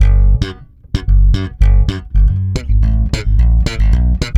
-AL DISCO G#.wav